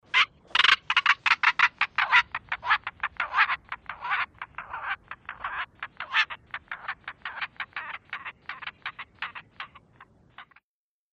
Korhaan|African | Sneak On The Lot